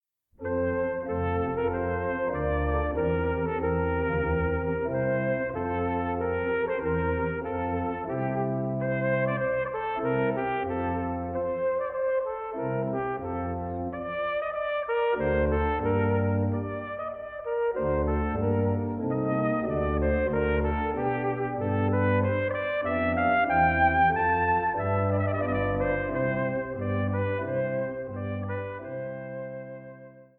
Trumpet
Organ